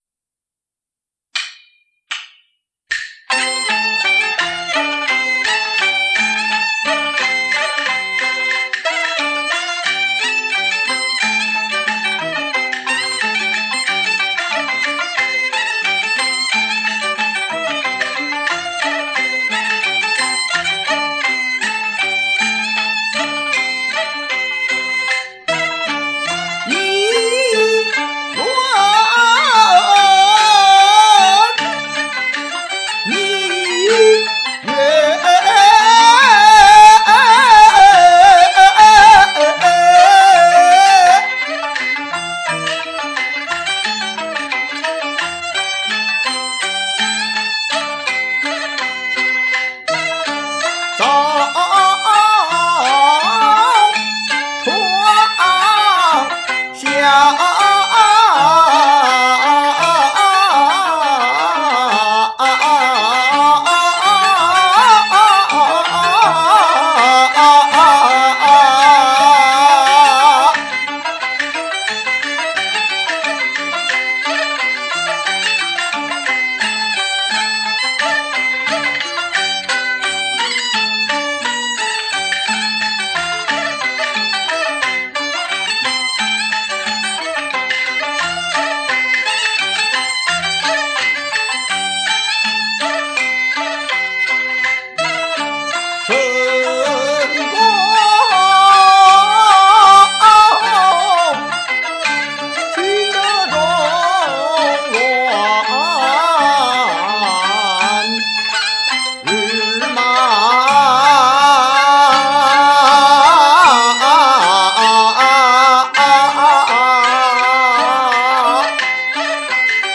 【夏季音樂會】
【二黃慢板】
【原板】